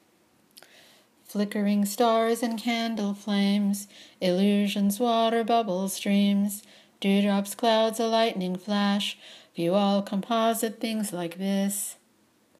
My translation above (with audio) is less elegant but allows it to be chanted to more or less the same tune used in the morning service at PTC.